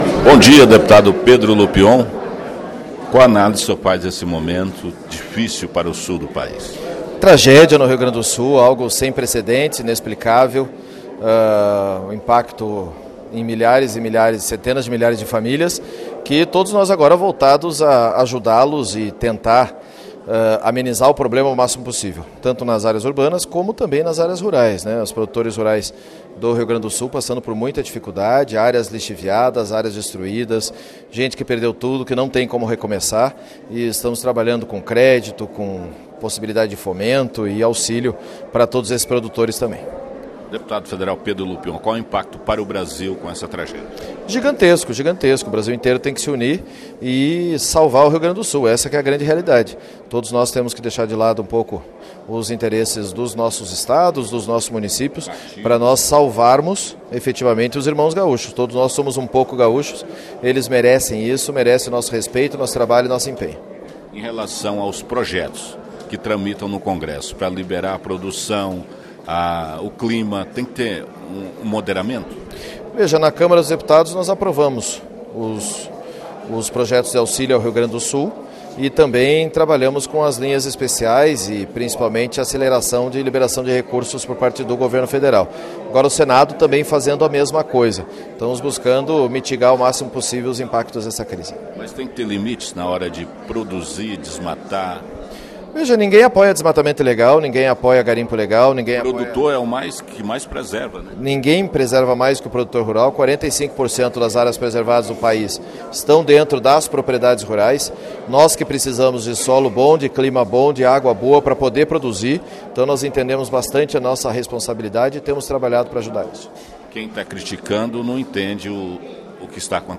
ouviu o parlamentar.